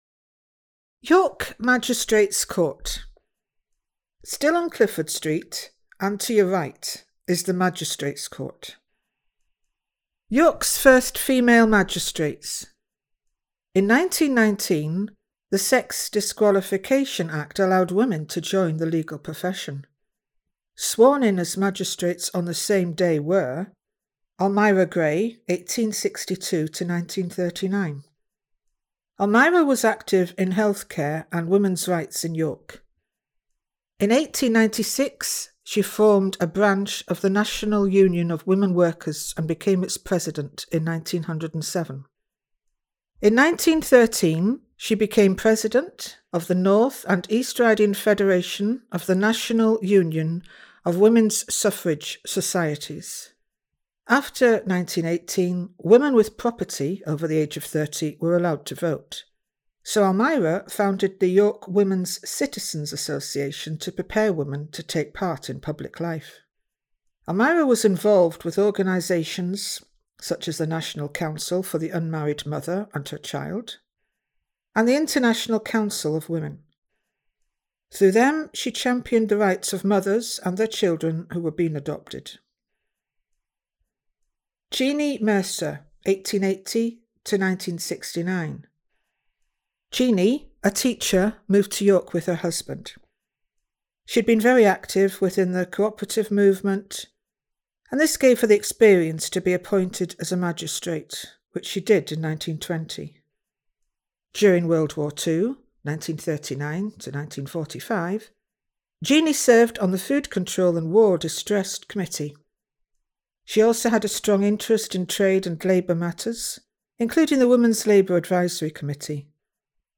The tour itself, is narrated by another inspirational women